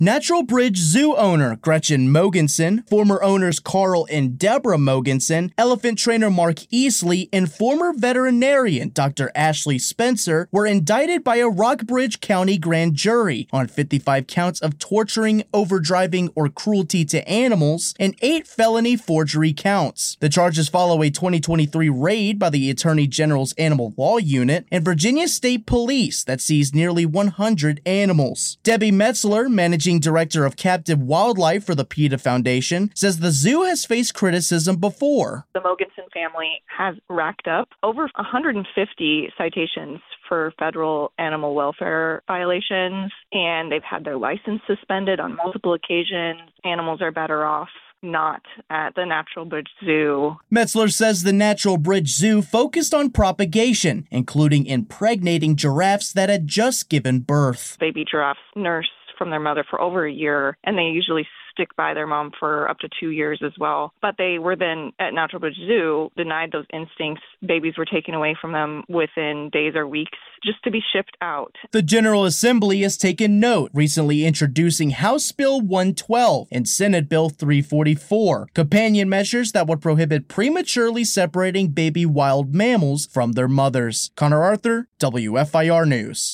2-9-PETA-Zoo-Response-Wrap-1-WEB.mp3